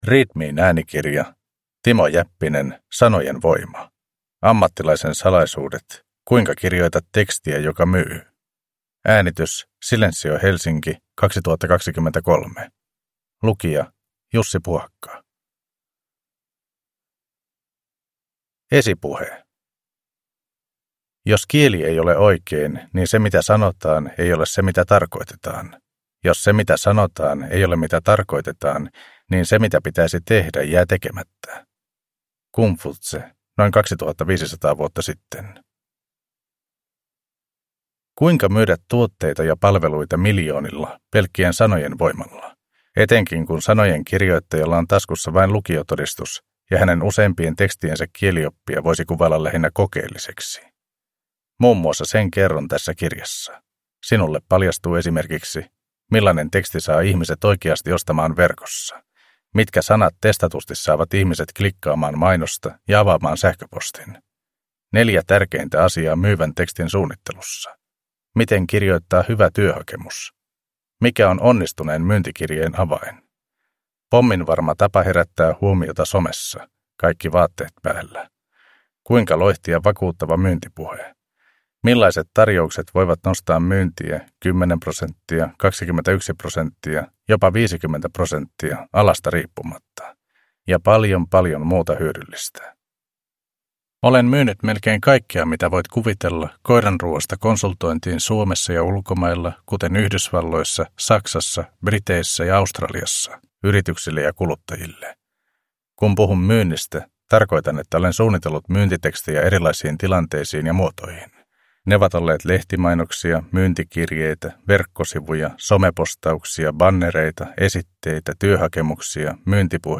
Sanojen voima: Ammattilaisen salaisuudet, kuinka kirjoitat tekstiä, joka myy – Ljudbok